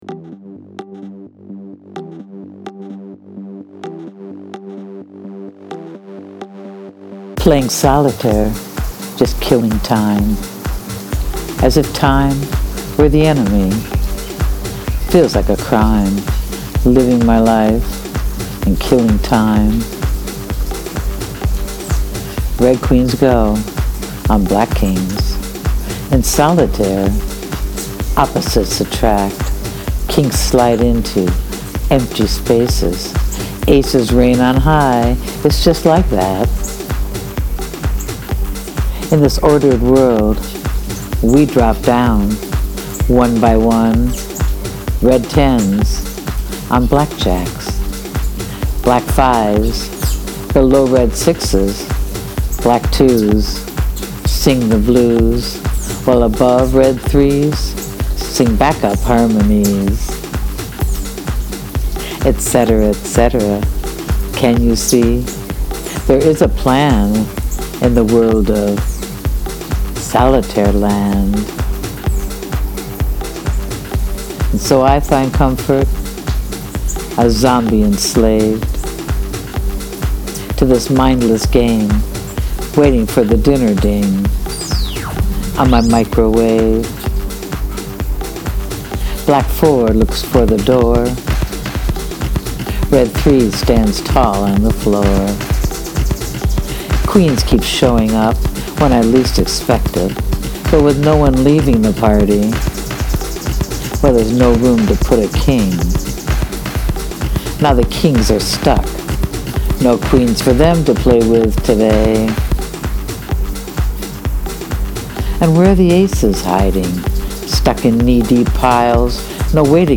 * Footnote” This was just recorded in a rainstorm with lots of bird sounds..such is my life:):) Please Press Play to hear my spoken word to my own Garage Band Beat:)
And the music and narration are perfect.
Very much enjoyed the poem and I actually love when there’s rain/bird sounds in the background 😊